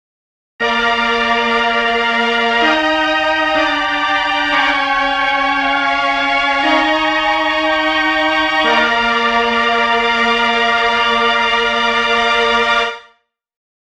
01-Mellotron Strings 1 L
01-Mellotron-Strings-1-L.mp3